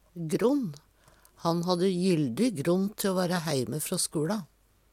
gronn - Numedalsmål (en-US)